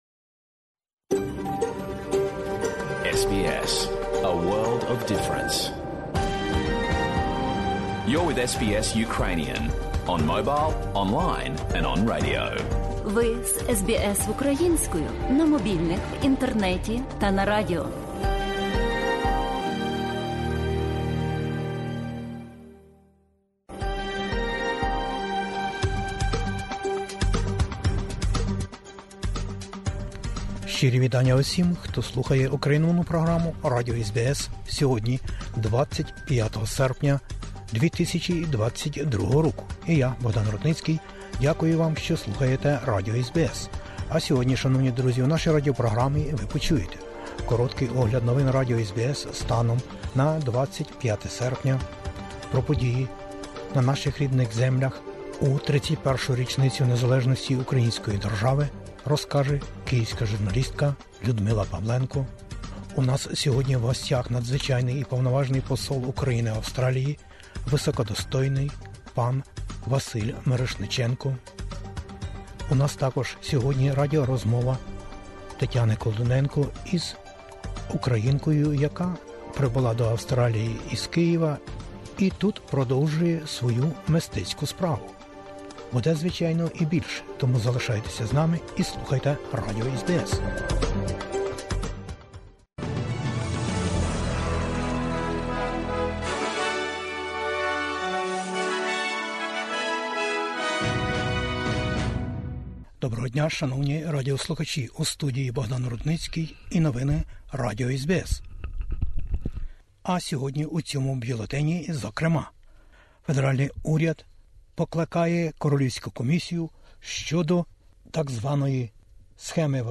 Огляд новин в Австралії, Україні та світі. Україна та українці у світі відзначили День Незалежності.